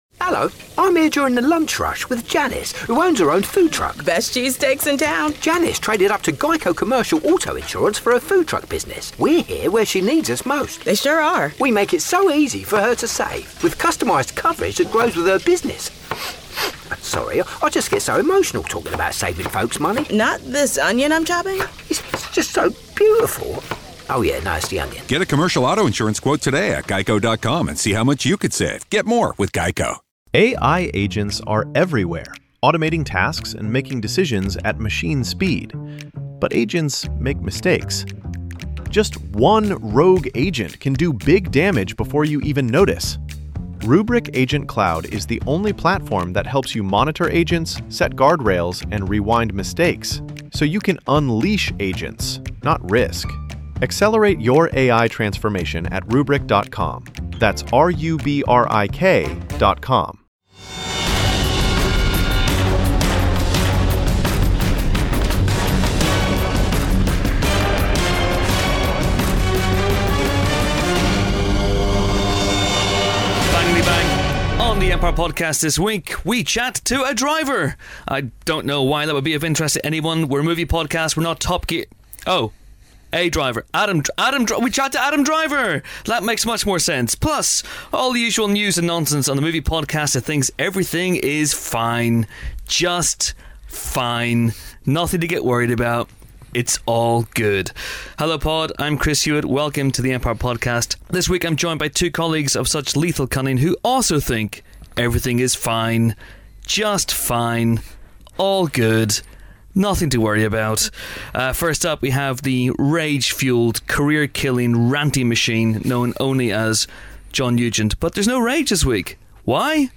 #237: Adam Driver The Empire Film Podcast Bauer Media Tv & Film, Film Reviews 4.6 • 2.7K Ratings 🗓 11 November 2016 ⏱ 72 minutes 🔗 Recording | iTunes | RSS 🧾 Download transcript Summary Kylo Ren himself, Adam Driver, pops by the pod booth this week to talk about playing a driver in Paterson.